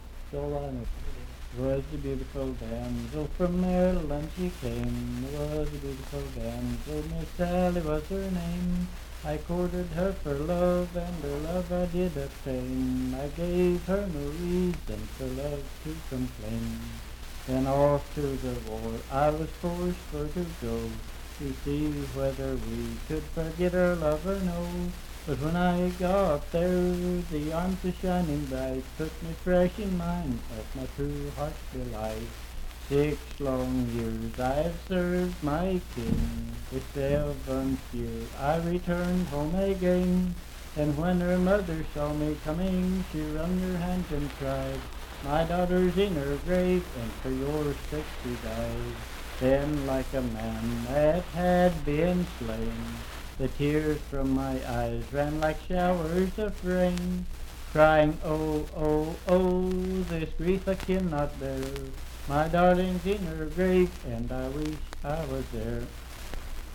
Unaccompanied vocal music
Voice (sung)
Pendleton County (W. Va.)